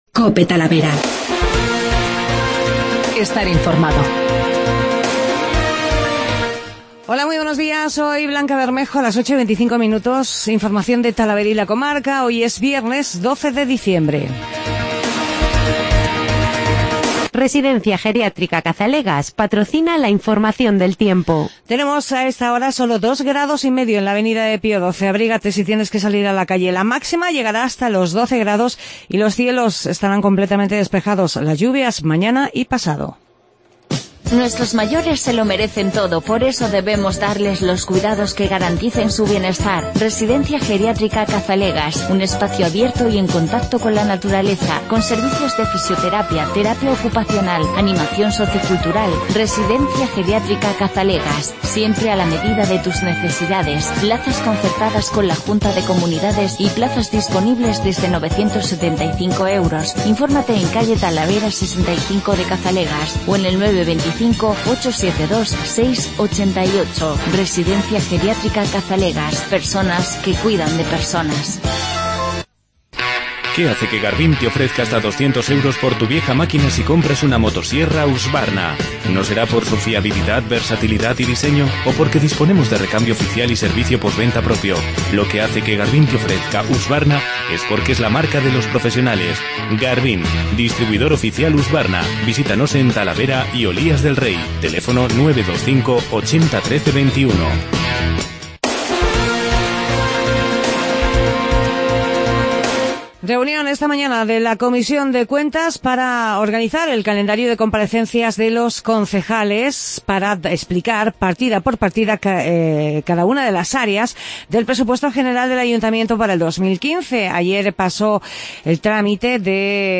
INFORMATIVO MATINAL
El concejal de hacienda, Arturo Castillo, explica en qué se van a invertir los dos millones de euros del préstamos que va a solicitar el Ayuntamiento.